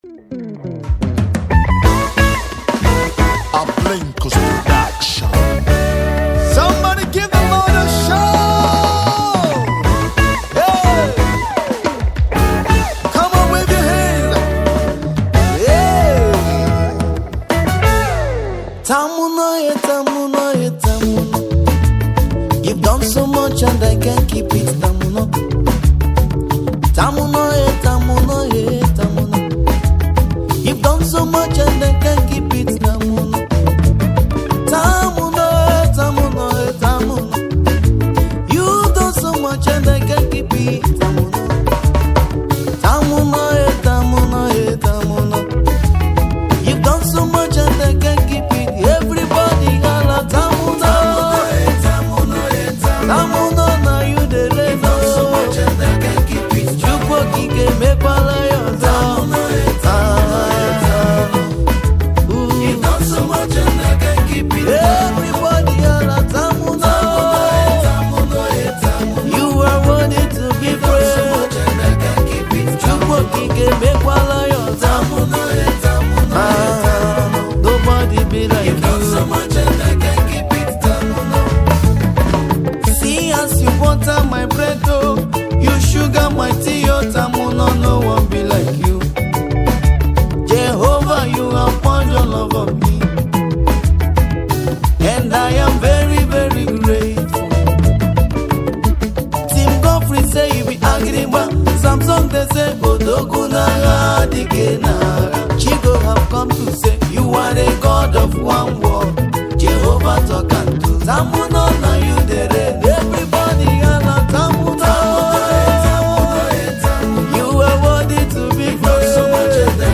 Igbo Gospel Music
soul-stirring anthem